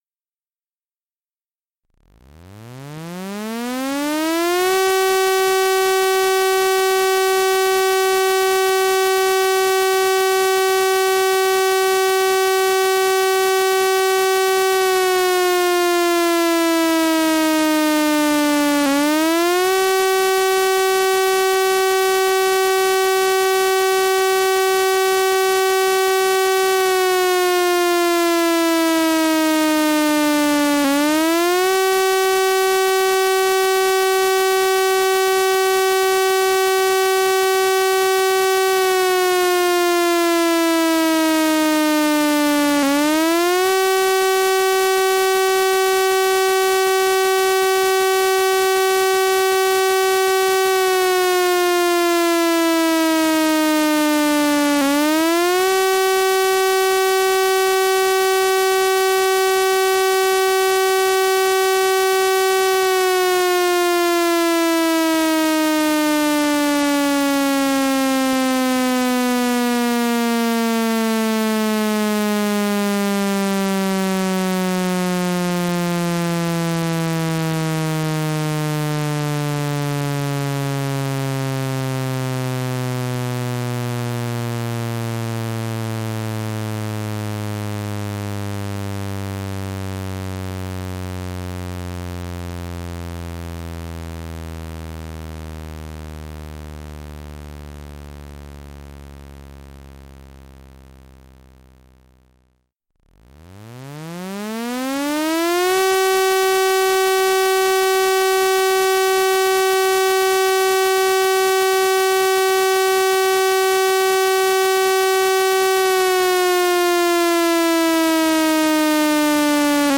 Familiarisez-vous avec la sirène d’alerte
Début de l’alerte | 3 courbes sonores | 3 fois 1mn 41s
Le début de l’alerte est un son modulé des sirènes de l’usine : son montant et descendant qui comporte 3 cycles de 1mn 41s.
sirene-alerte.mp3